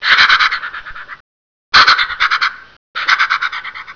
Hubertus Lockpipa Tjädertupp
Detta imiterar släpljudet vid leken.